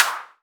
edm-clap-35.wav